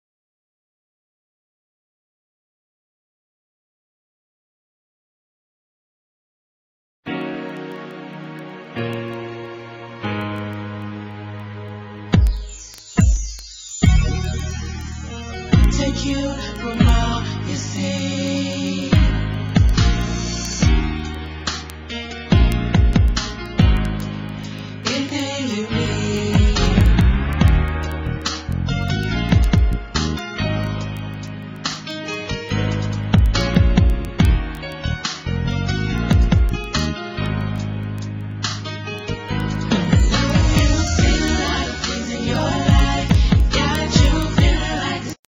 NOTE: Background Tracks 1 Thru 8